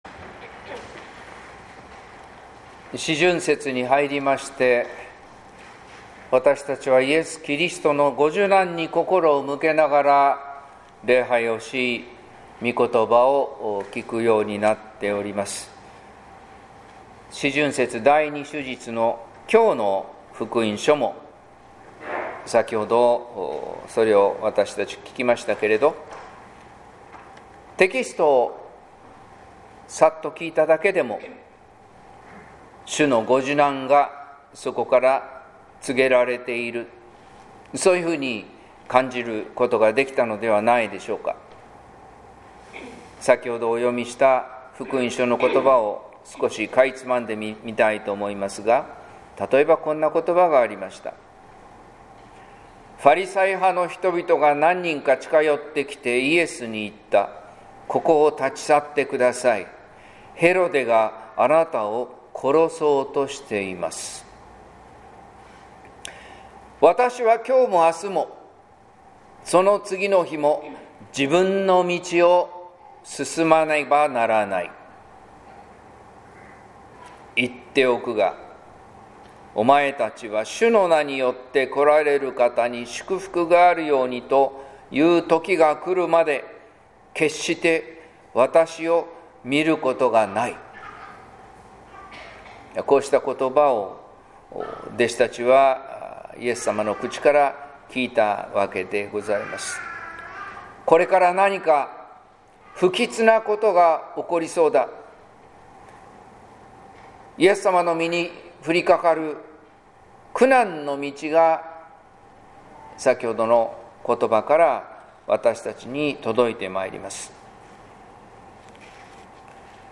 説教「めん鳥が雛を集めるように」（音声版）